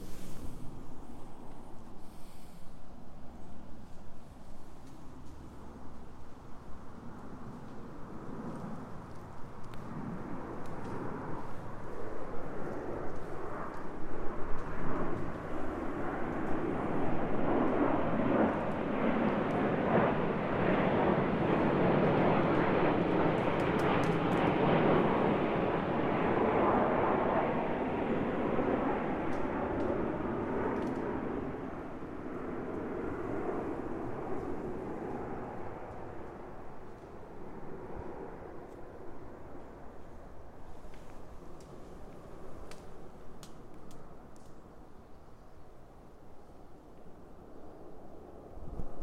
商用飞机 飞行中的声音
描述：商业航班从凤凰城飞往洛杉矶。主要内舱的长记录。可以大声听到发动机。在达到10,000英尺时，乘务员通过对讲机发出飞行指令。
标签： 飞行服务员 飞机 一个irline 飞机 飞机 飞机 飞行 公告 发动机
声道立体声